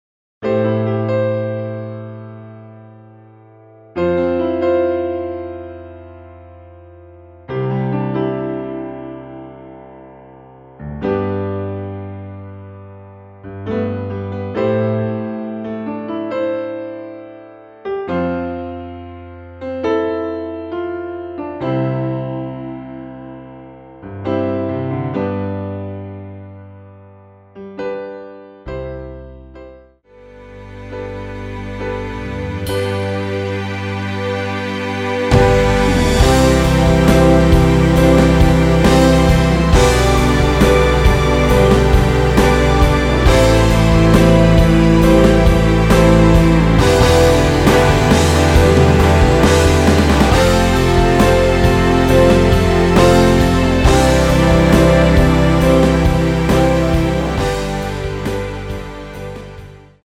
음정은 반음정씩 변하게 되며 노래방도 마찬가지로 반음정씩 변하게 됩니다.
앞부분30초, 뒷부분30초씩 편집해서 올려 드리고 있습니다.
곡명 옆 (-1)은 반음 내림, (+1)은 반음 올림 입니다.